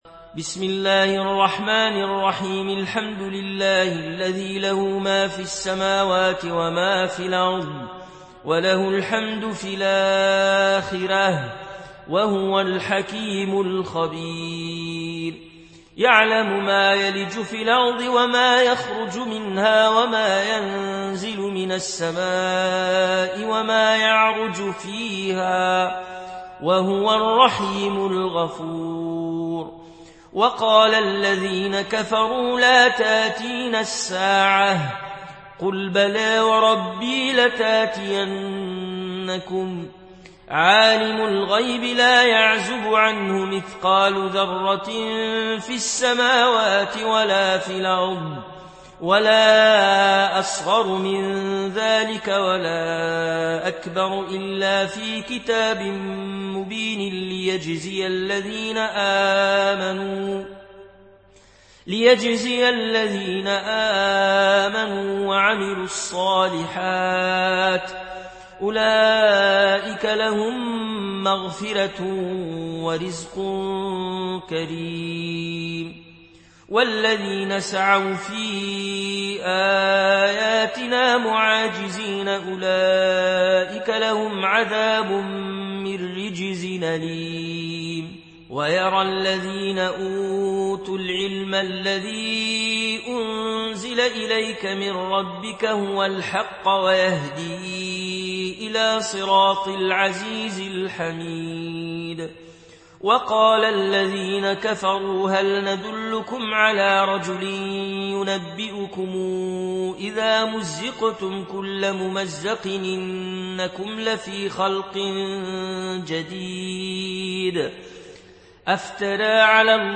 Riwayat Warsh